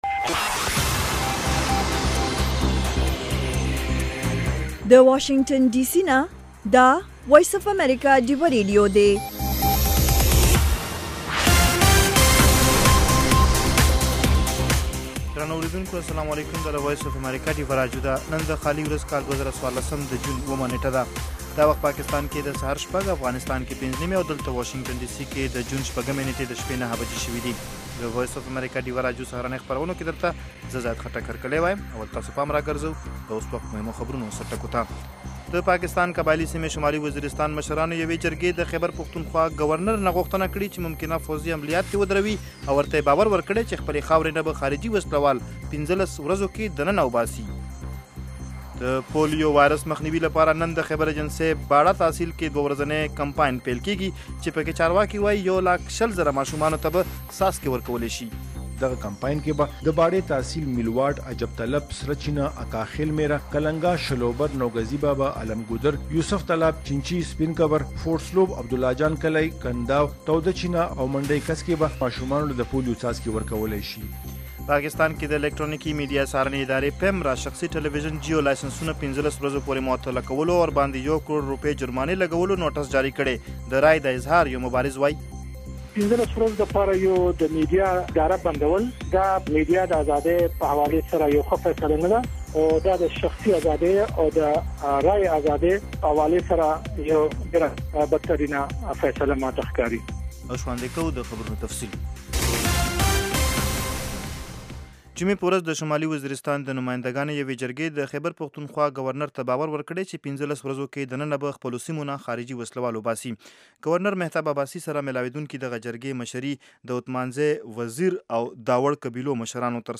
خبرونه - 0100
د وی او اې ډيوه راډيو سهرنې خبرونه چالان کړئ اؤ د ورځې د مهمو تازه خبرونو سرليکونه واورئ.